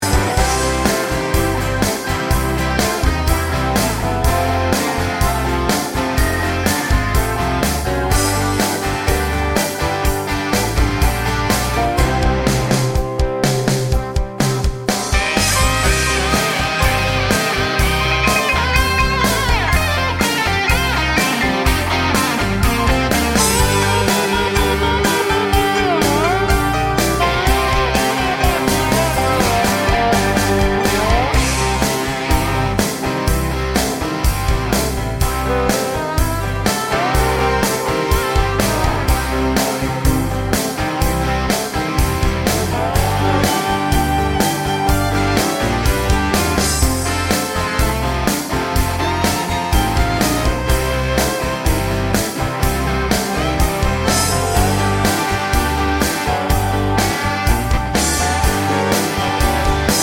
Full Version Country (Male) 5:06 Buy £1.50